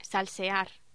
Locución: Salsear
Sonidos: Voz humana